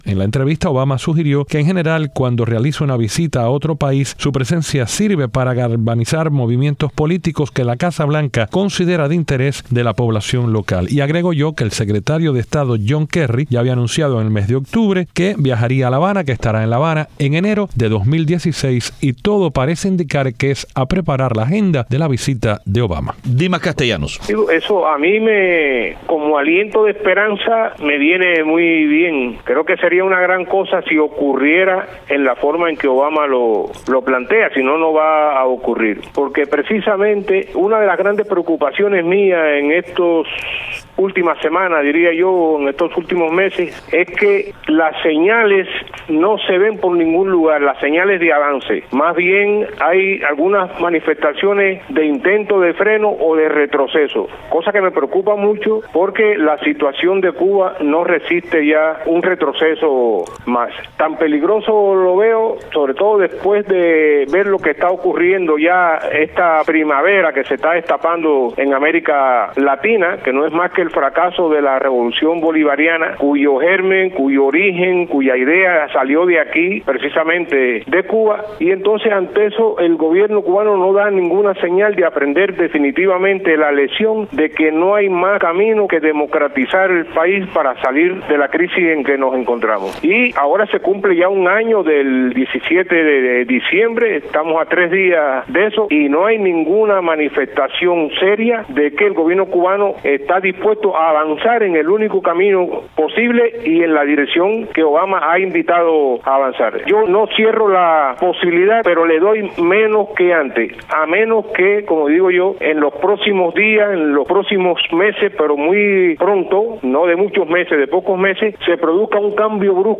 Periodista independiente